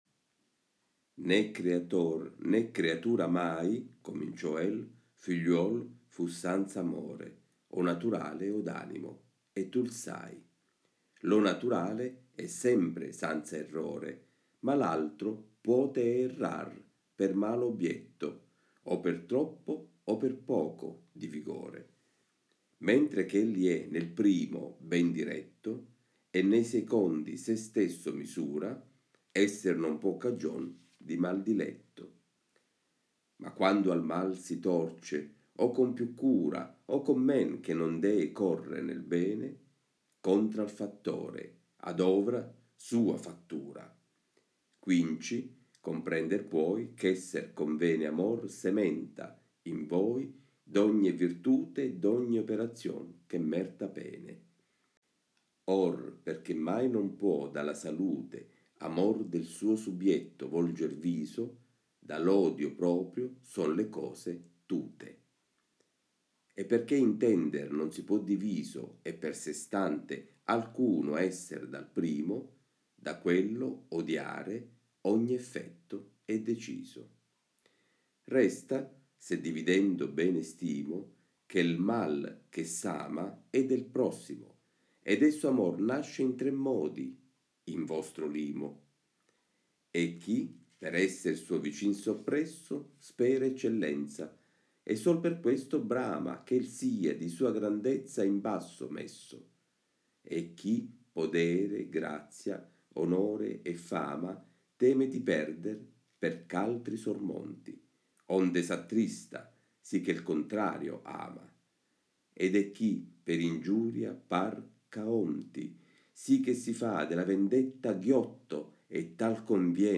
AUDIOLETTURE DANTESCHE, 2: PURGATORIO – L’ORDINE DELL’AMORE E IL “MAL CHE S’AMA”